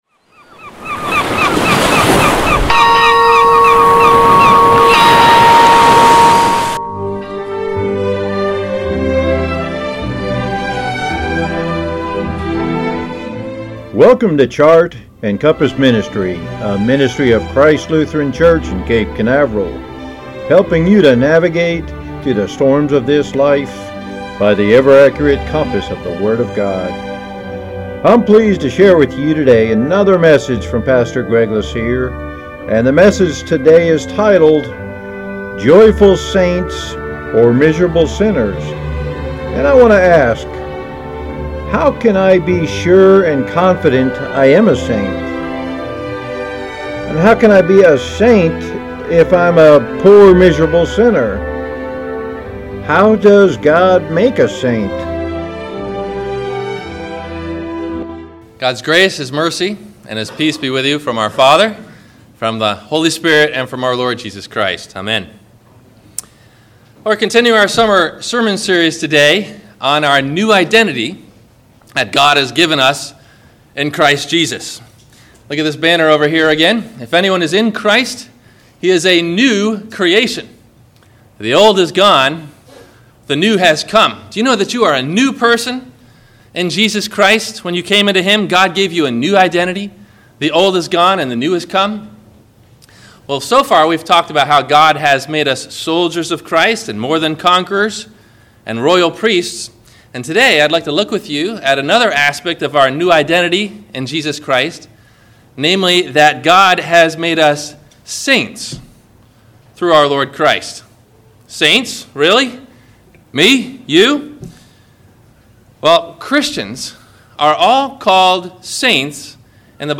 Questions asked before the Message: